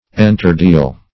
Search Result for " enterdeal" : The Collaborative International Dictionary of English v.0.48: Enterdeal \En"ter*deal`\, n. [Enter- + deal.] Mutual dealings; intercourse.